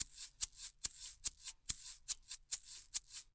TYR SHAKER 1.wav